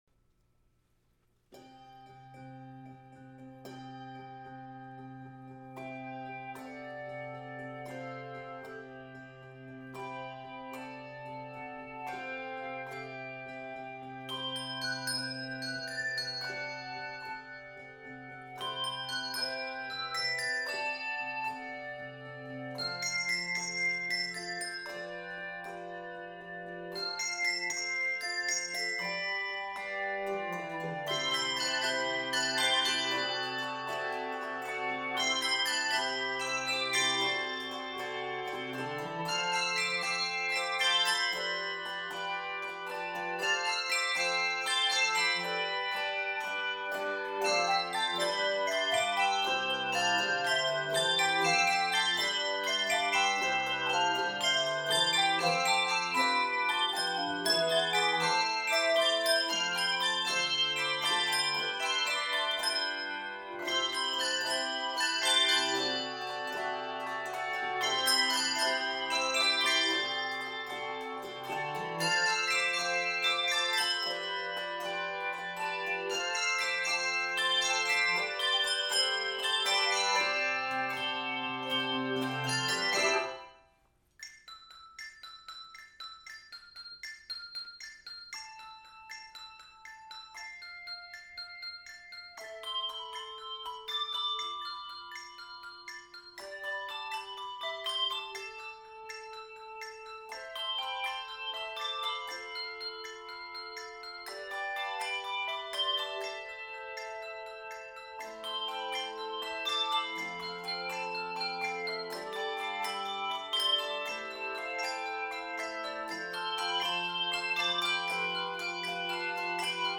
energetic
N/A Octaves: 3-5 Level